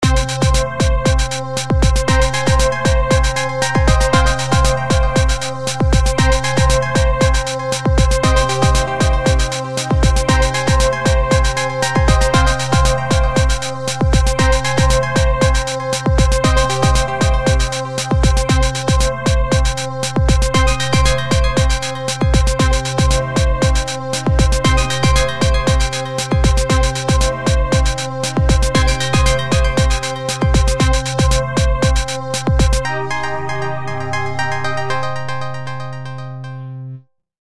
Genre(s): Electronic, Instrumental
Instrumentation: Drums, Synth